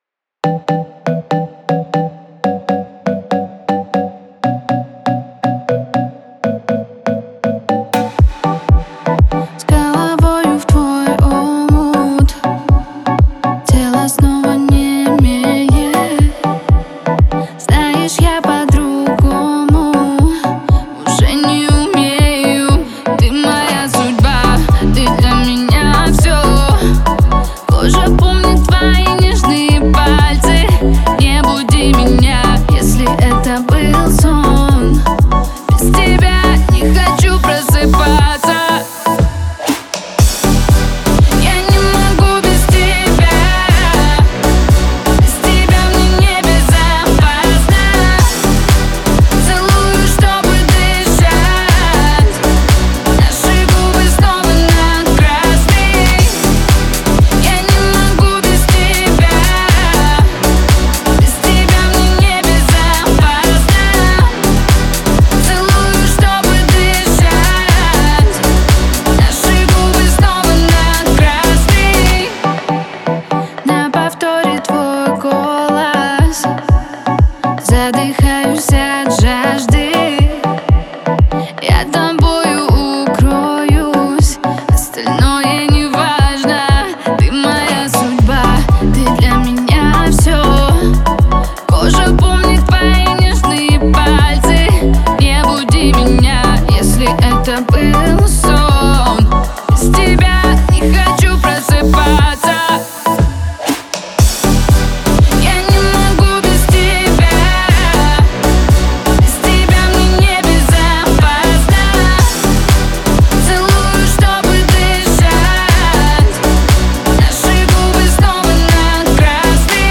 отличается ритмичными битами и запоминающимися мелодиями